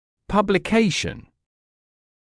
[ˌpʌblɪ’keɪʃn] [ˌпабли’кейшн] публикация